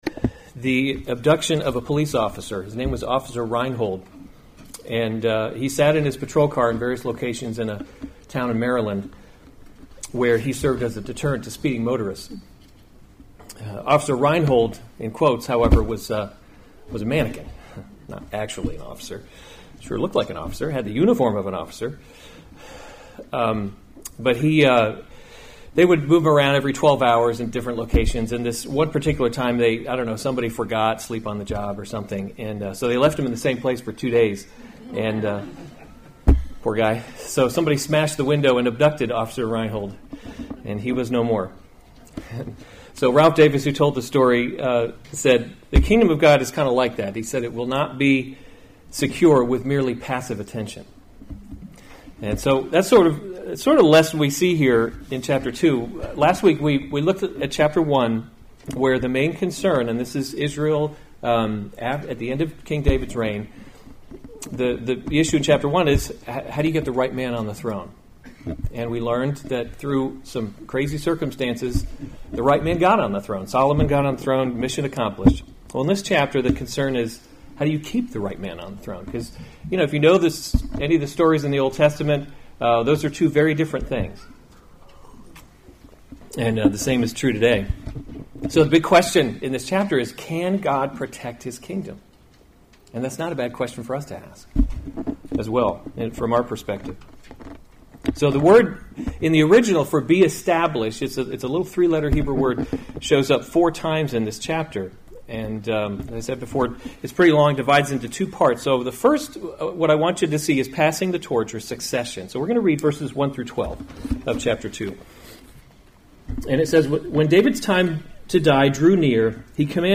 September 15, 2018 1 Kings – Leadership in a Broken World series Weekly Sunday Service Save/Download this sermon 1 Kings 2 Other sermons from 1 Kings David’s Instructions to Solomon […]